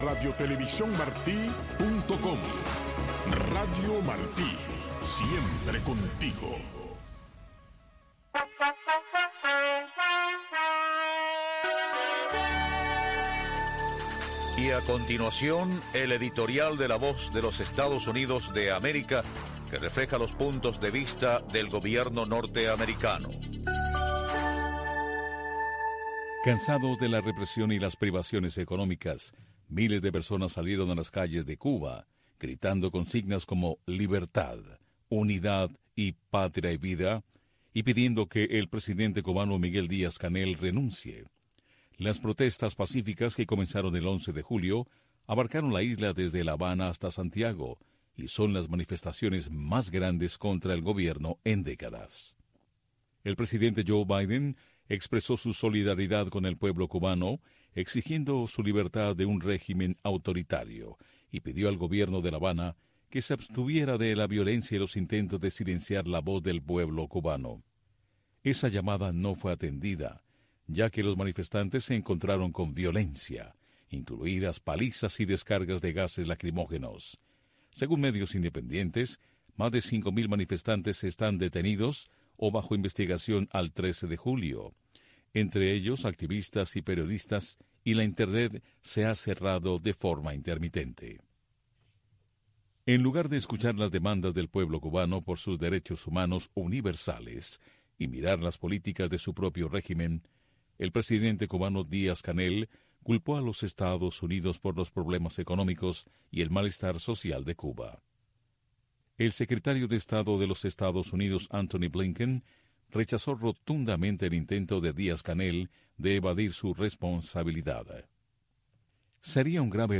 Radio Martí les ofrece una revista de entrevistas, información de la actualidad mundial vista desde el punto de vista, Entre Dos Rios.